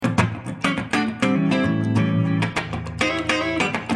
Funk guitars soundbank 1
Guitare loop - funk 27